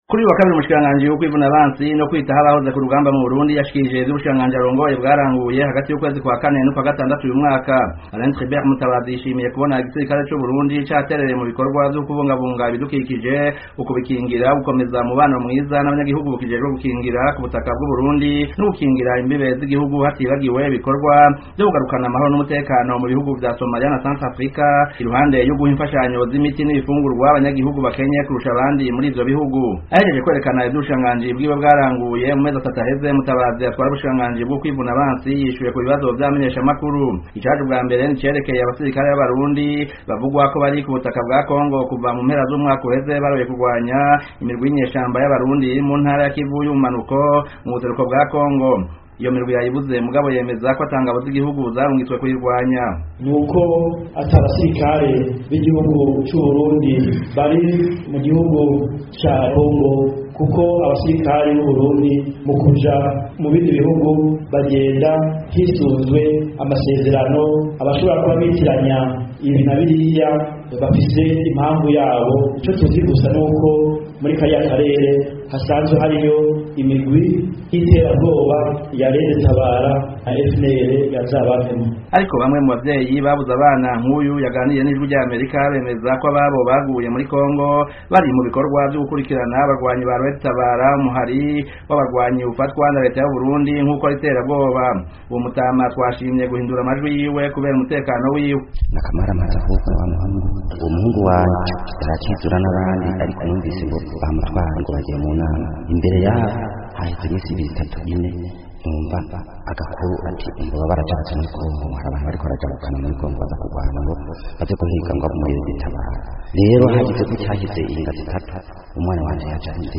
Amajwi ya Bamwe mu Bemeza ko Baburiye Ababo muri Kongo